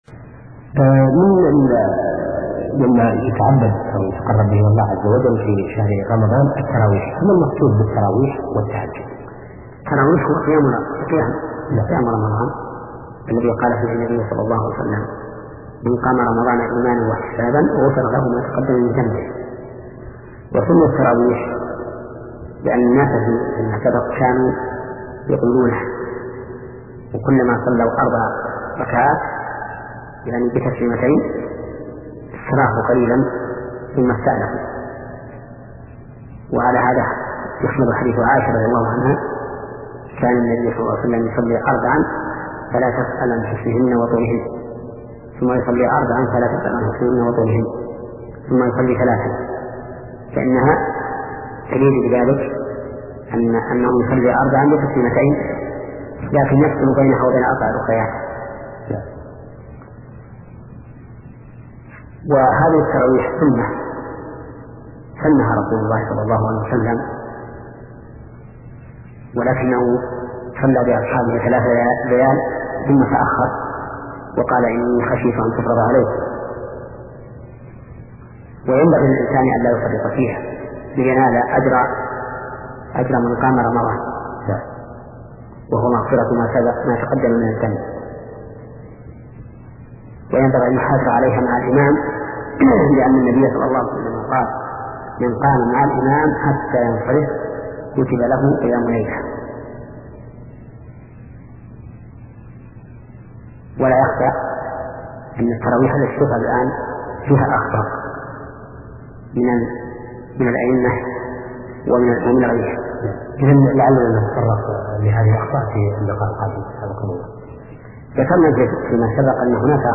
فقه العبادات [65] - للشيخ : ( محمد بن صالح العثيمين ) من الأحكام المهمة في الصوم ما يتعلق بصوم المسافر، وكيفية إحياء ليالي رمضان وخاصة ليلة القدر، والأحكام المتعلقة بالاعتكاف.